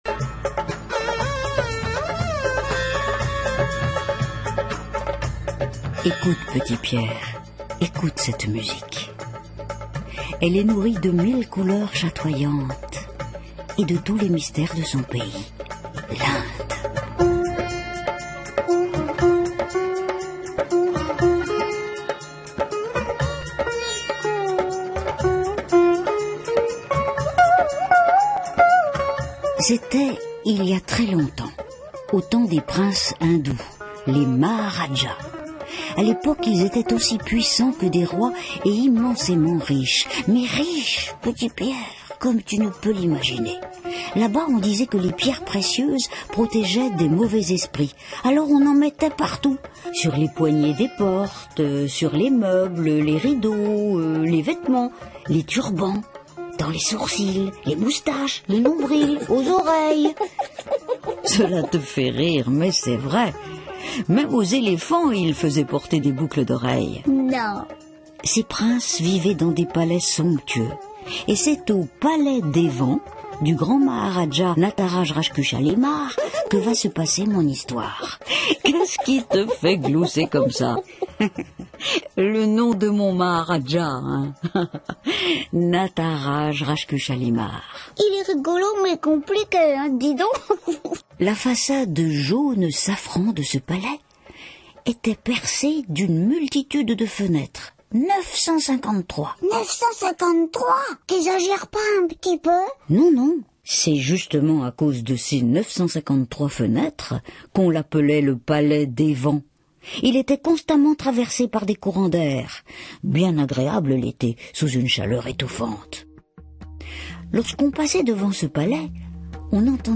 Jeunesse, Roman jeune, Roman jeune + CD